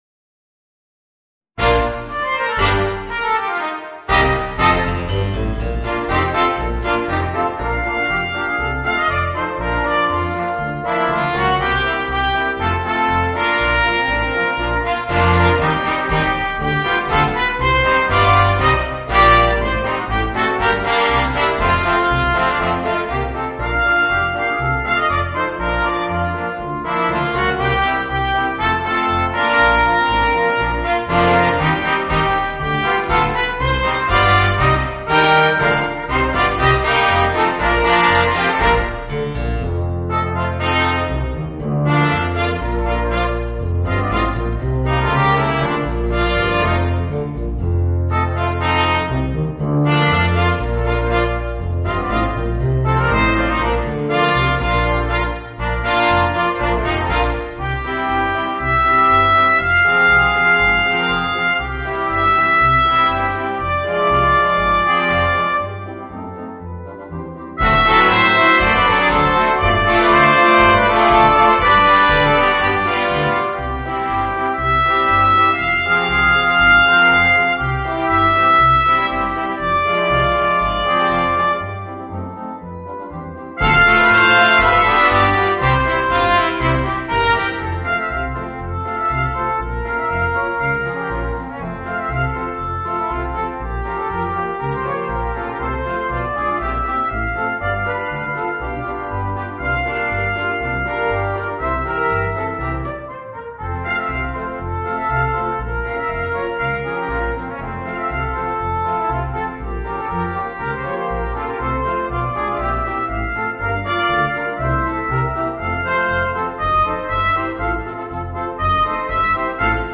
Voicing: 3 Trumpets and 2 Trombones